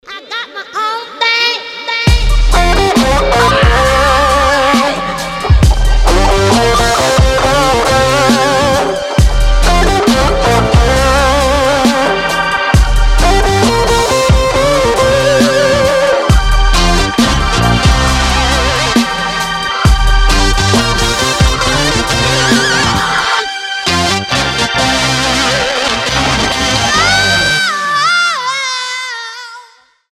• Качество: 320, Stereo
женский голос
электрогитара
RnB
soul
funk
Стиль: фанк, соул